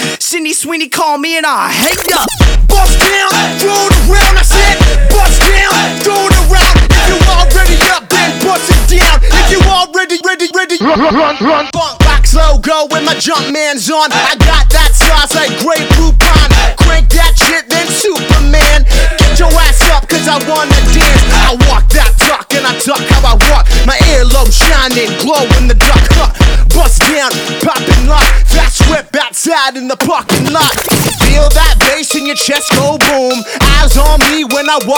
Жанр: Хип-Хоп / Рэп / Альтернатива
Alternative, Hip-Hop, Rap